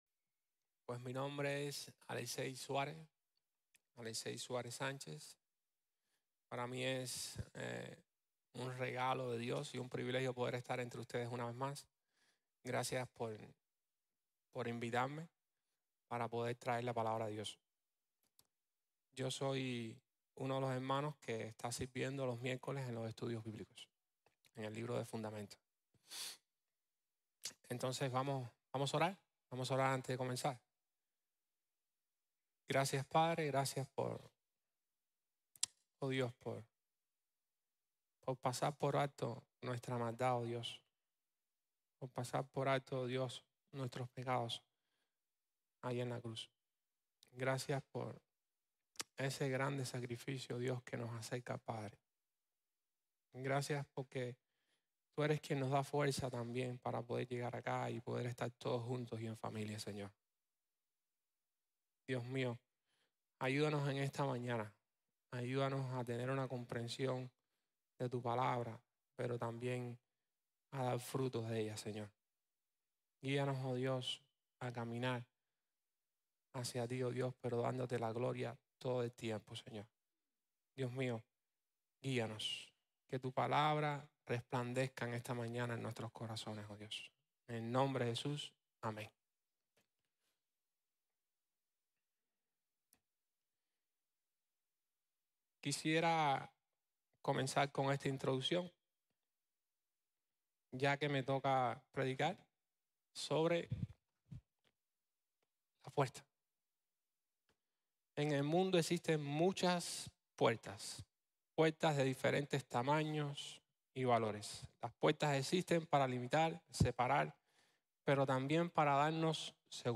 Yo soy la puerta | Sermon | Grace Bible Church